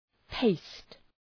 {peıst}
paste.mp3